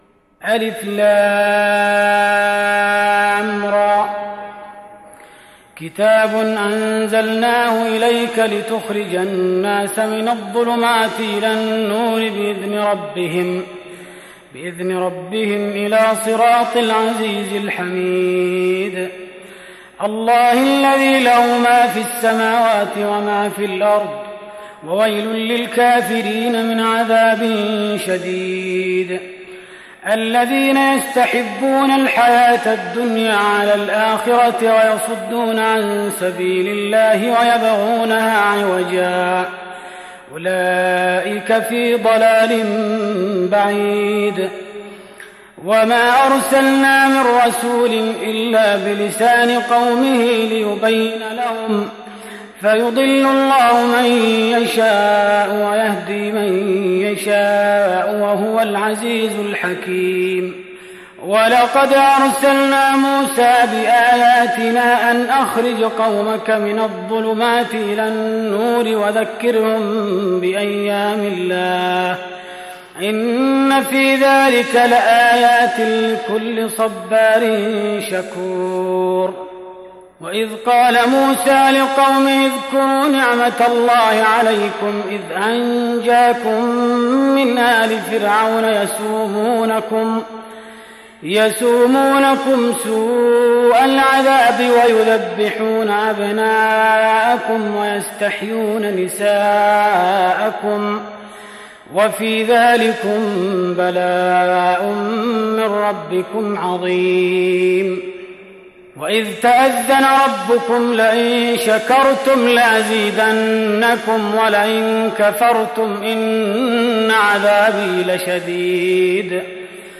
تهجد رمضان 1417هـ سورة إبراهيم Tahajjud Ramadan 1417H from Surah Ibrahim > تراويح الحرم النبوي عام 1417 🕌 > التراويح - تلاوات الحرمين